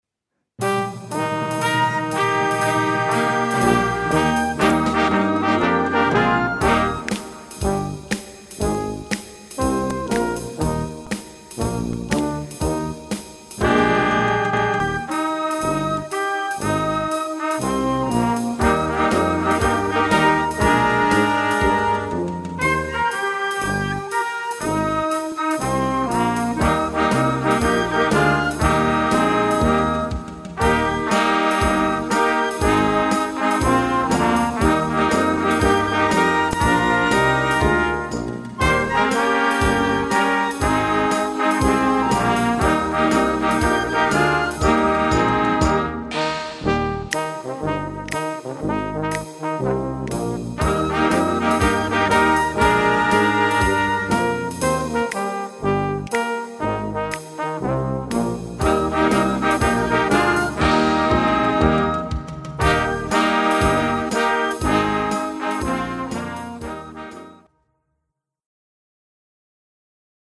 Gattung: 4-Part Music
A4 Besetzung: Blasorchester PDF